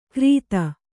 ♪ krīta